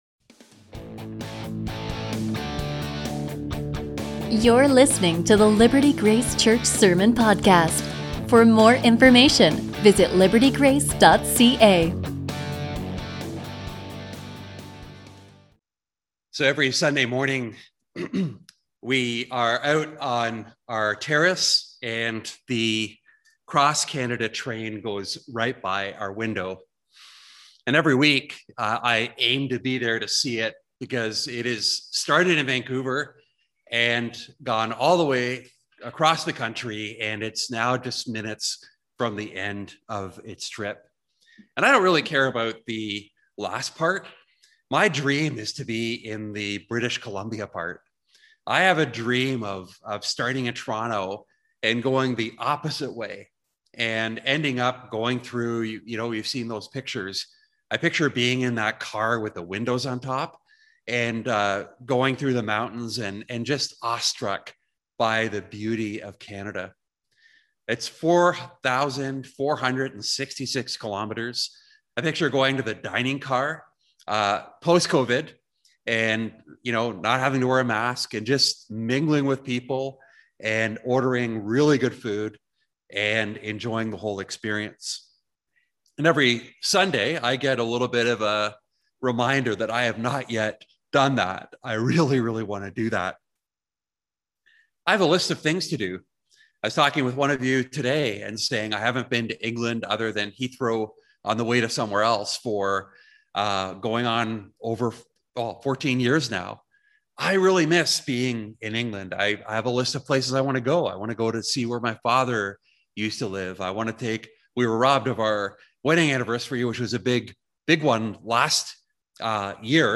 A message from the series "Homesick."